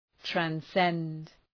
Προφορά
{træn’send}